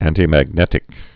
(ăntē-măg-nĕtĭk, ăntī-)